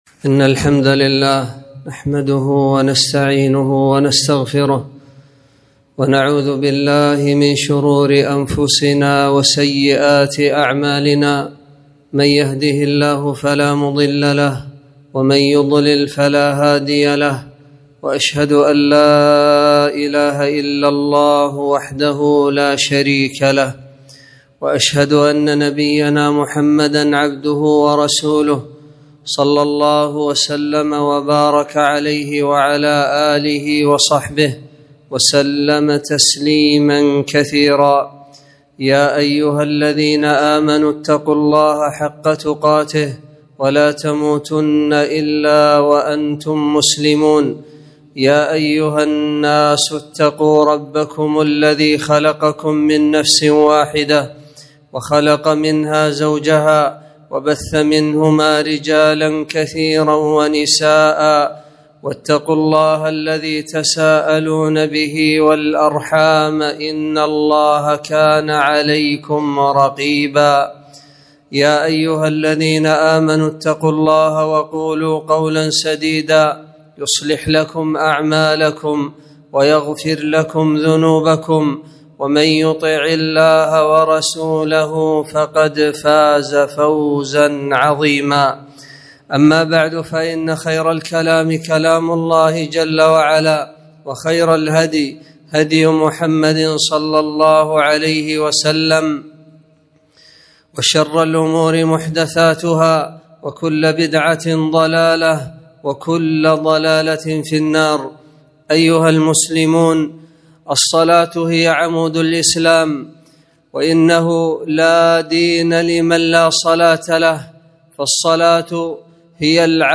خطبة - الوضوء فضله وصفته